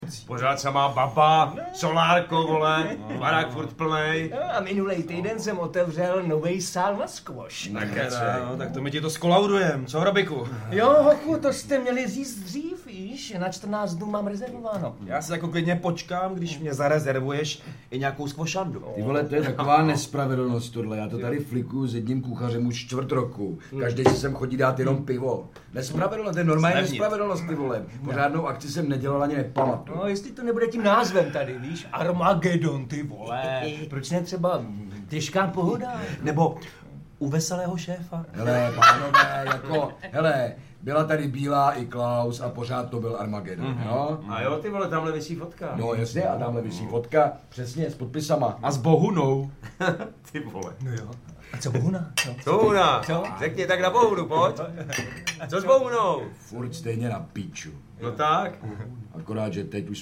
Audiobook
Read: Lukáš Hlavica